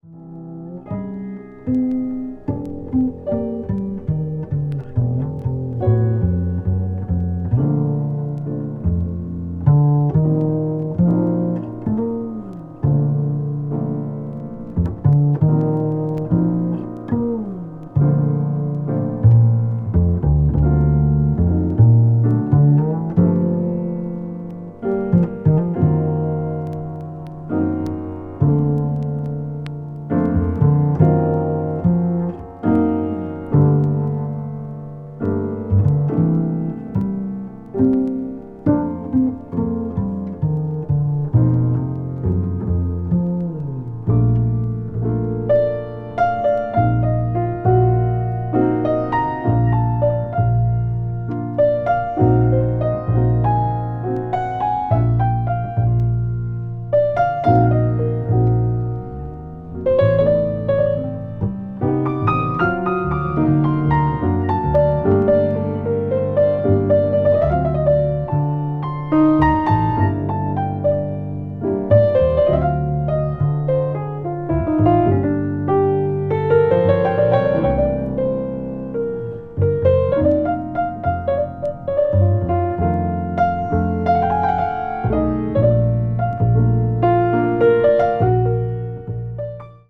contemporary jazz   modal jazz